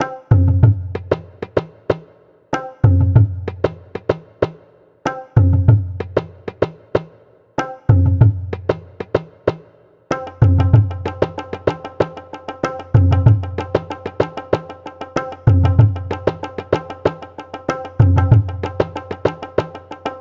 描述：这是挪威特隆赫姆上坡建筑的声音。它的目的是帮助骑自行车的人沿着街道爬上陡峭的山坡。
用Zoom H1记录。
标签： 场记录 循环的PERC 环境 机械 广泛的传播 奇怪 打击乐循环 研磨 街道 金属 左TO-正确的记录 发现声 打击乐 即兴 自行车 工业 音响 节奏
声道立体声